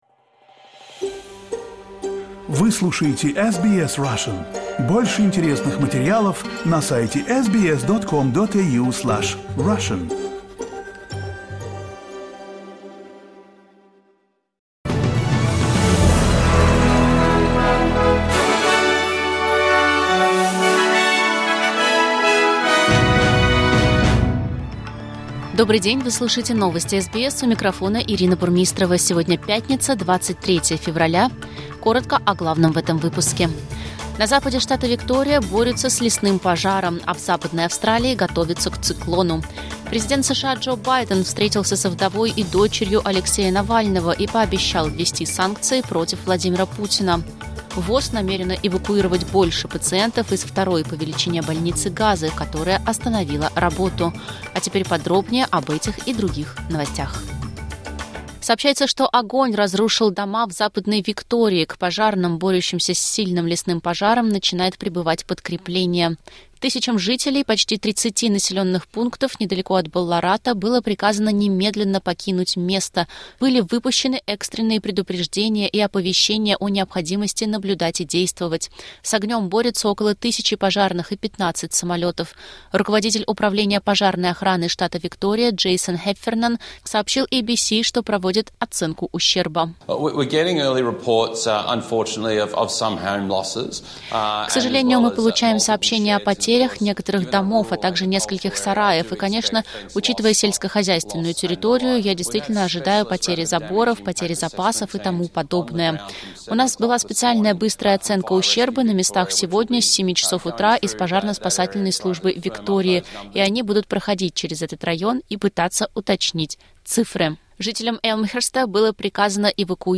SBS news in Russian — 23.02.2024